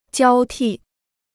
交替 (jiāo tì) Dictionnaire chinois gratuit